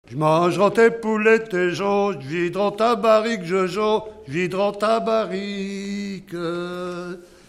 circonstance : bachique
Enregistrement de chansons
Pièce musicale inédite